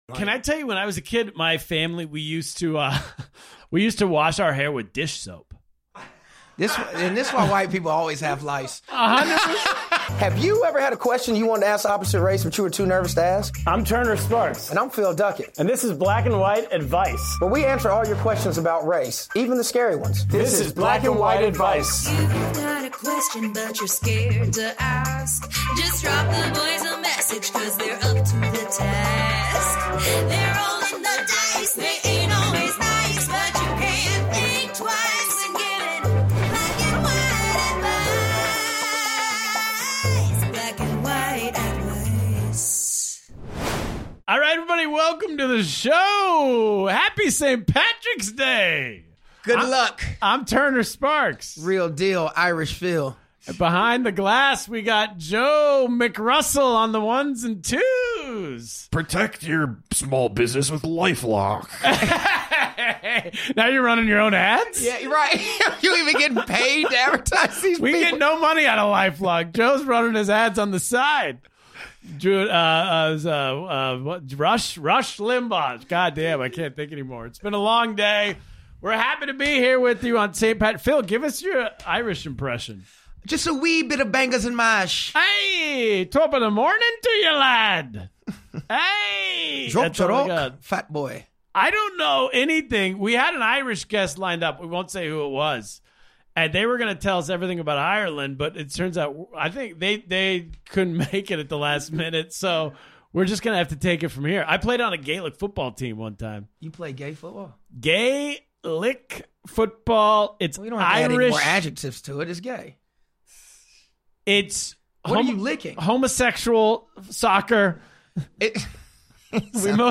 Comedy